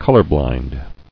[col·or·blind]